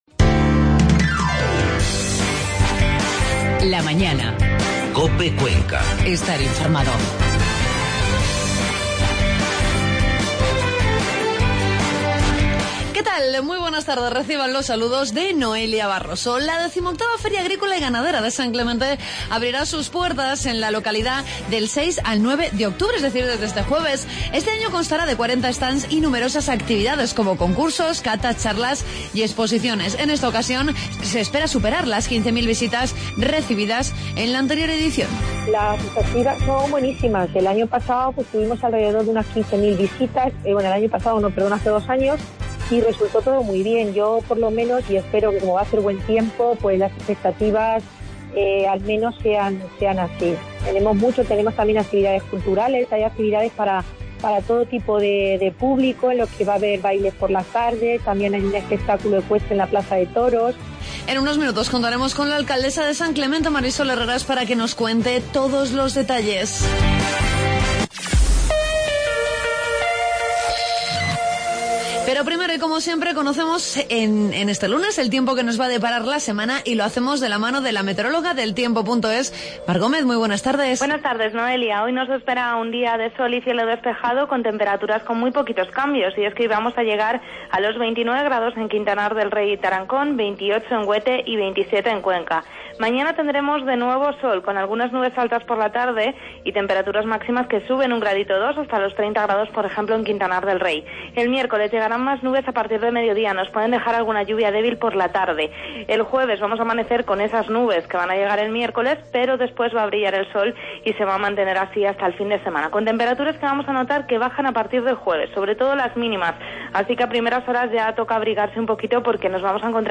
Conocemos todos los detalles de una nueva edición de la Feria Agrícola y Ganadera de San Clemente que se celebrará en la localidad del 6 al 9 de octubre. Hablamos con la alcaldesa del municipio, Mari Sol Herrera.